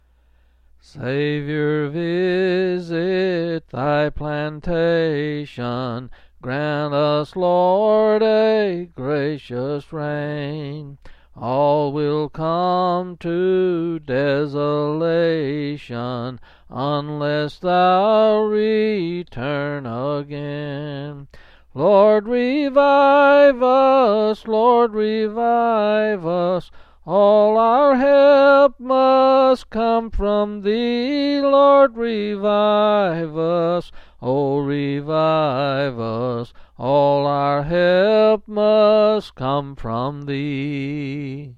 Quill Pin Selected Hymn
8s and 7s